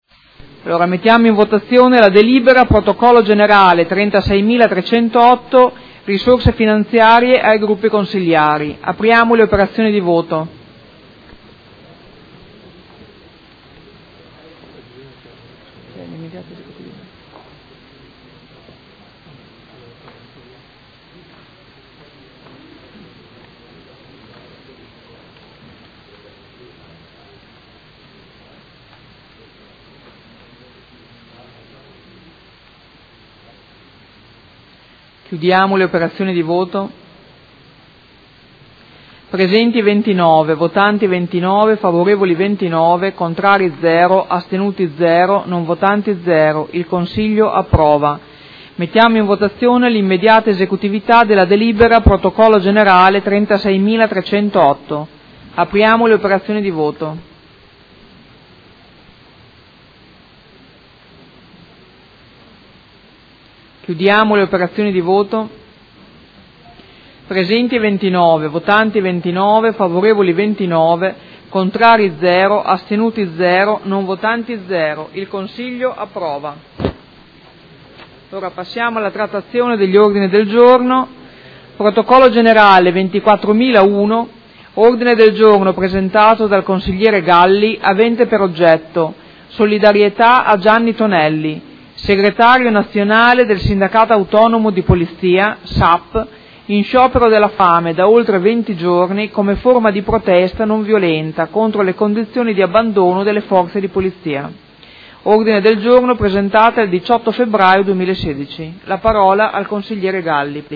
Seduta del 31/03/2016. Proposta di deliberazione: Risorse finanziarie ai Gruppi Consiliari – Mandato amministrativo 2014-2019 Anno 2016.